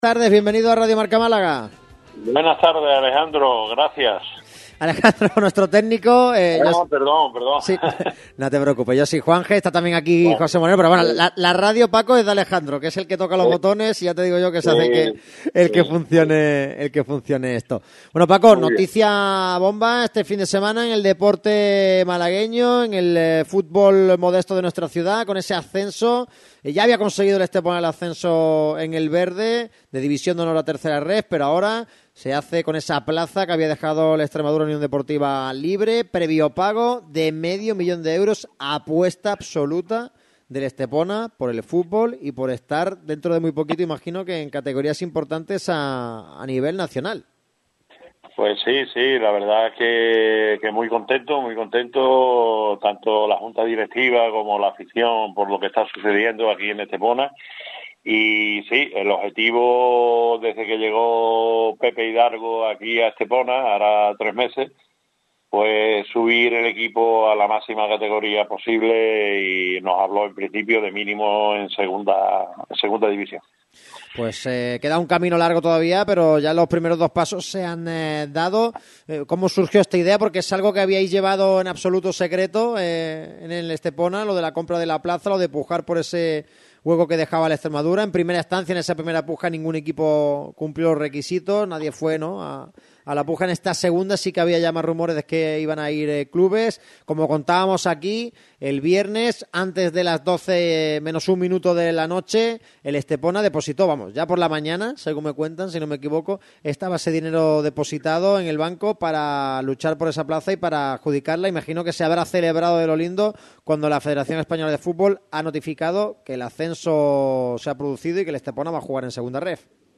pasa por el micrófono rojo de Radio Marca Málaga.